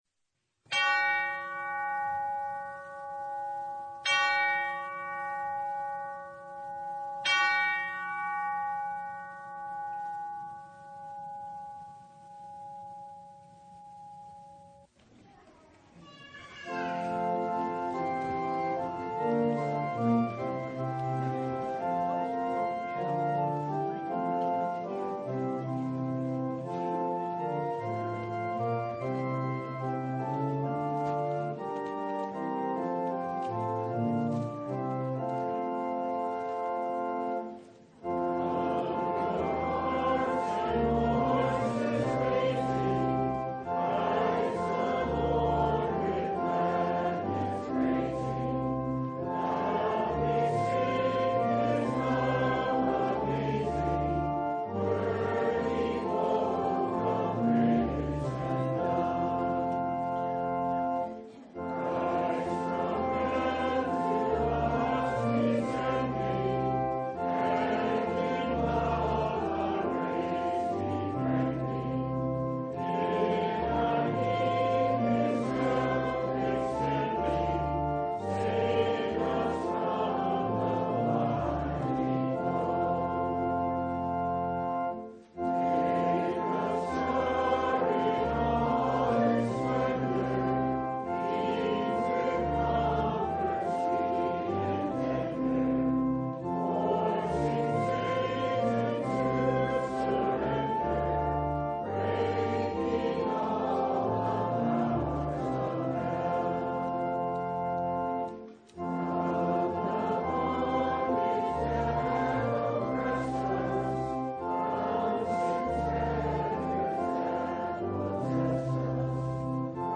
Christmas Eve Vespers (2025)
Full Service